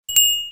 02 Camera.aac